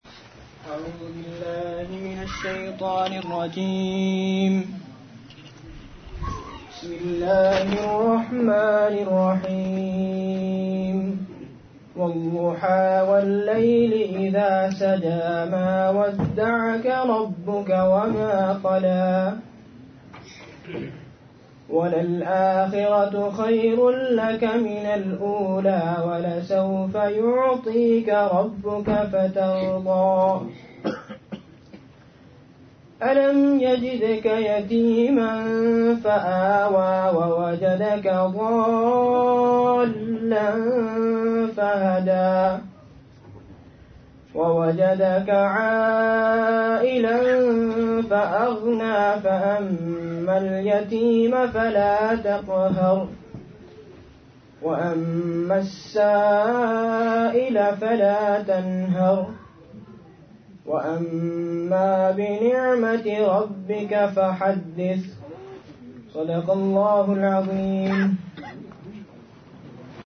Mercy To Mankind Clinic Inauguration Tilawat